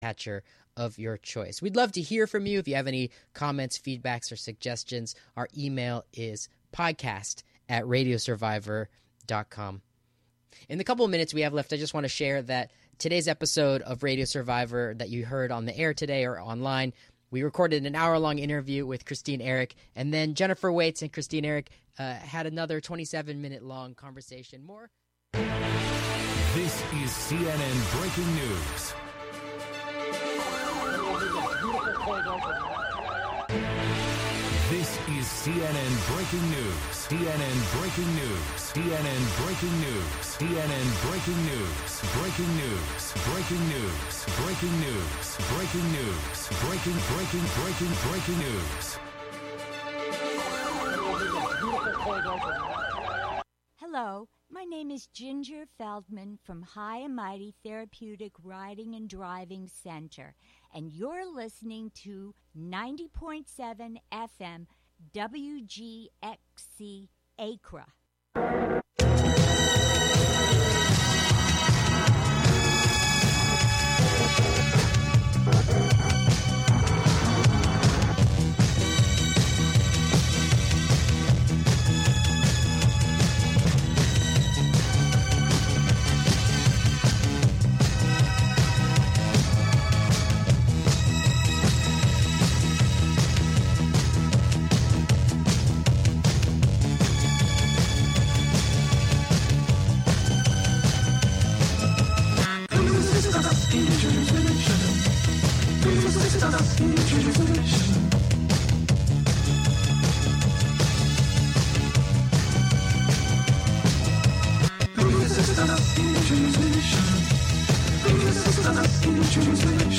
Contributions from many WGXC programmers.
S1 EP6 - Coda - Beyond the Western Door: Magnetic on Main Every season, we will probe deeper into the mysteries of the storyline through a mystical sound and text experiment, plunging us beyond language, beyond narrative, Beyond the Western Door.
The "WGXC Morning Show" is a radio magazine show featuring local news, interviews with community leaders and personalities, reports on cultural issues, a rundown of public meetings and local and regional events, with weather updates, and more about and for the community, made mostly through volunteers in the community through WGXC.